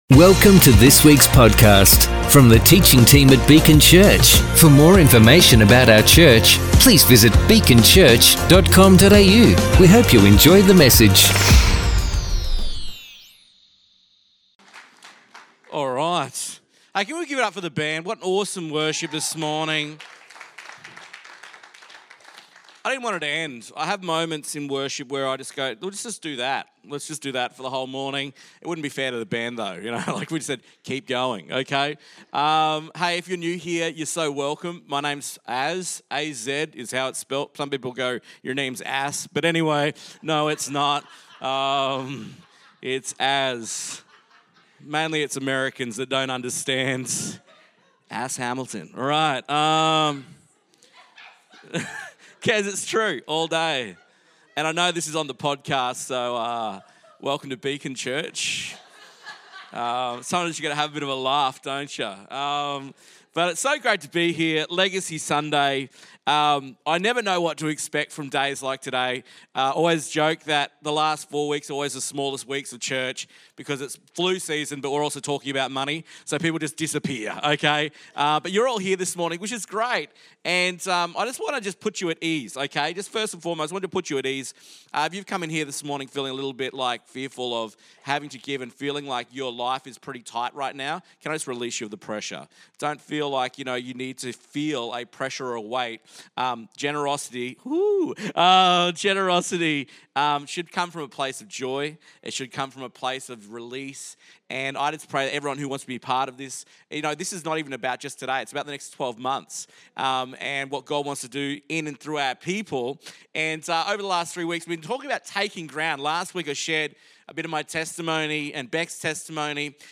Episode 21: Legacy Sunday Preach 2023